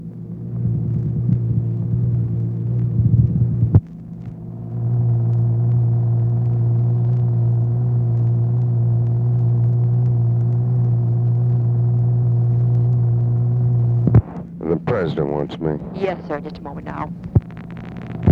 Conversation with TELEPHONE OPERATOR and ABE FORTAS
Secret White House Tapes